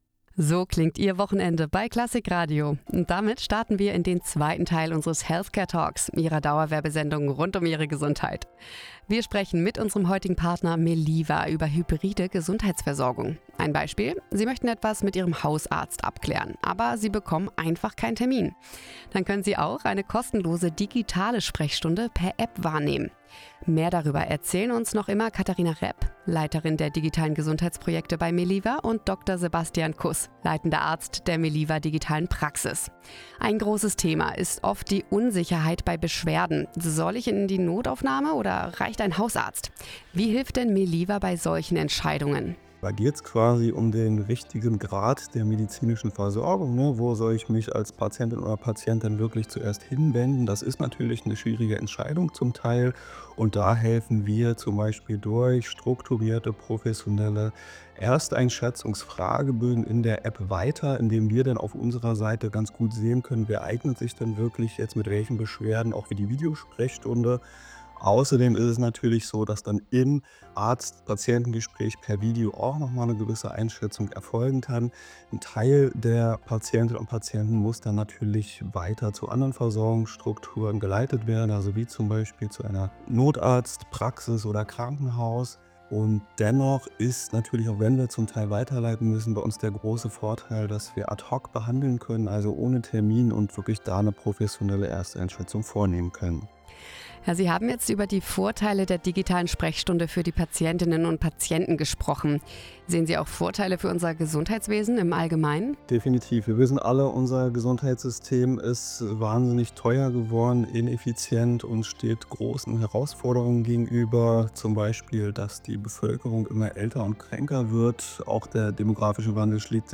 Our interview on Klassik Radio
Meliva-Healthcare-Talk-II.mp3